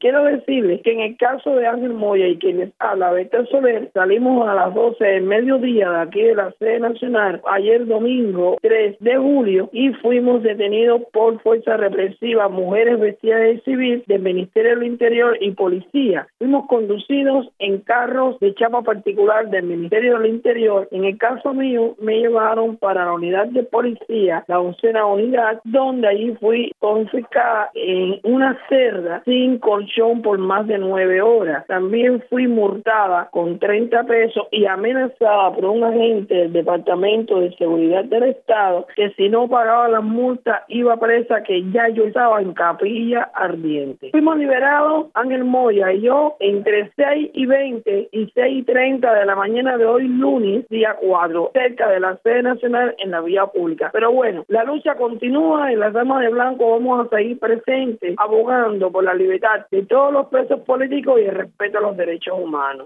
Declaraciones de Berta Soler, líder de las Damas de Blanco